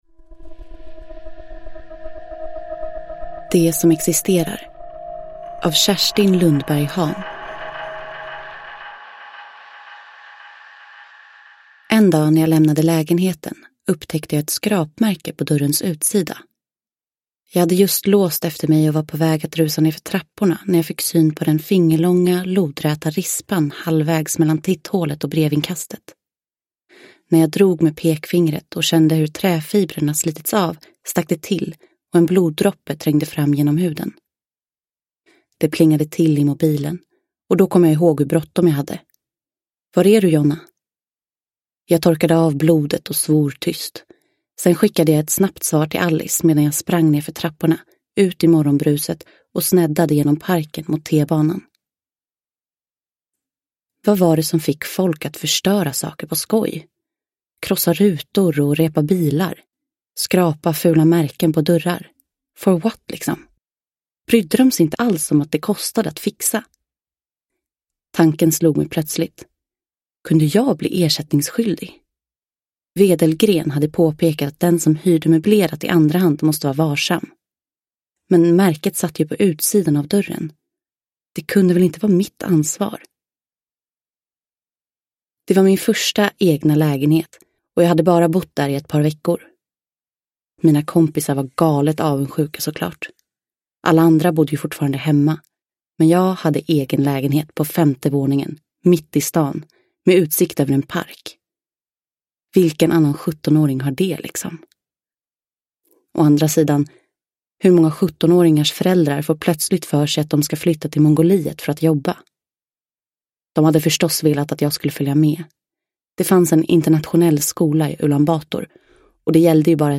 Det som existerar – Ljudbok